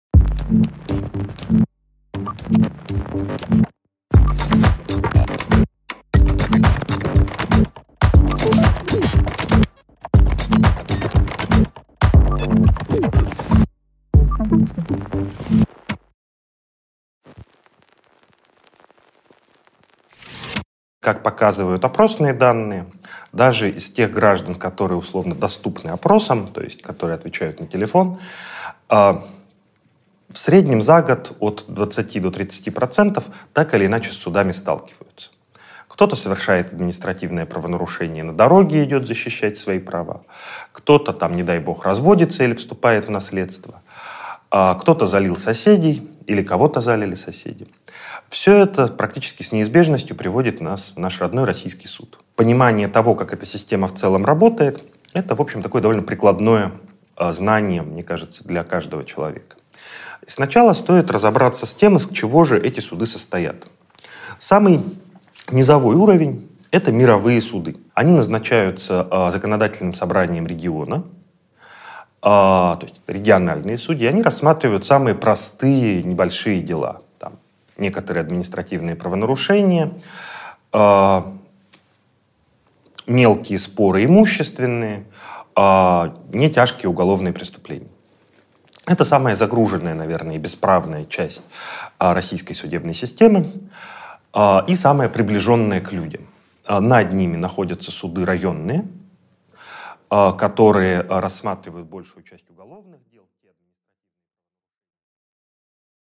Аудиокнига А судьи кто?